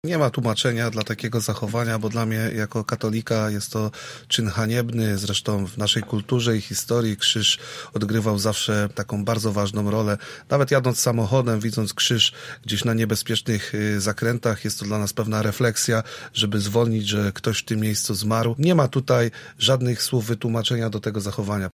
Goście „Soboty po 9” krytykują akt profanacji krzyża w naszym mieście.